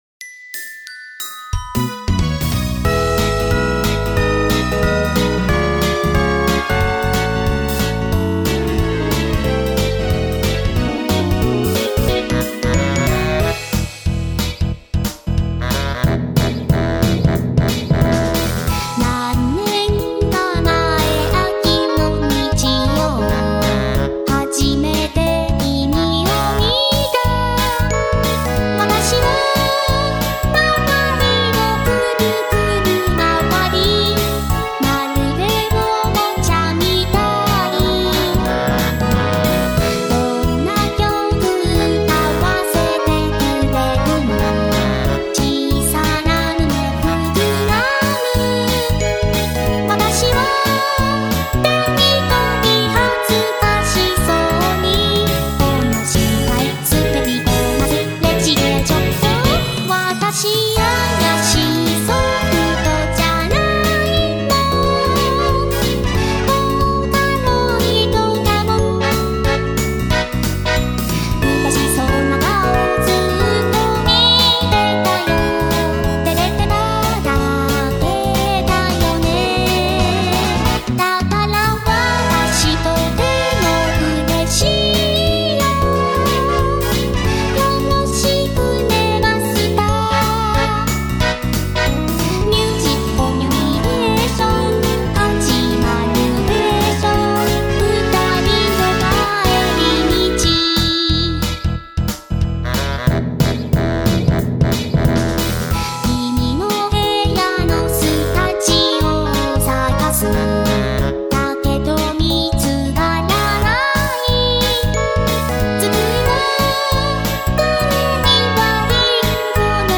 歌うソフトウェア音源「VOCALOID」を使った企画もの。